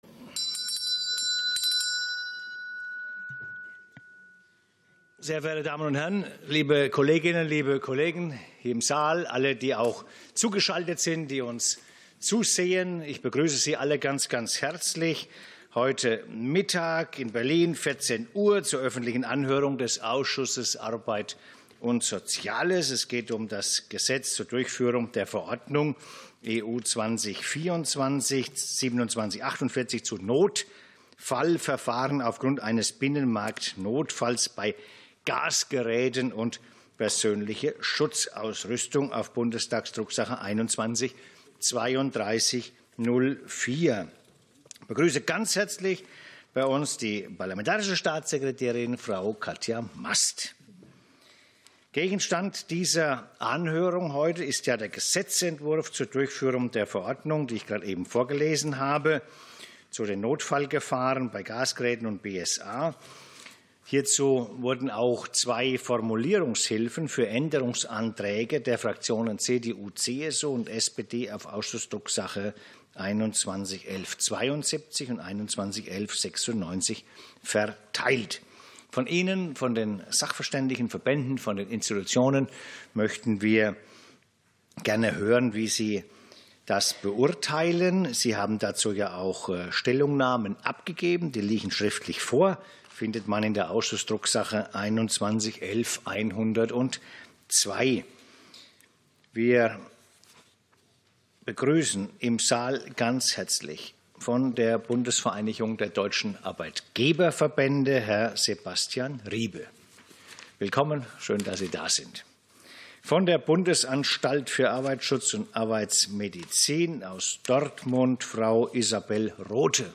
Anhörung zu Notfallverfahren bei Gasgeräten und PSA ~ Ausschusssitzungen - Audio Podcasts Podcast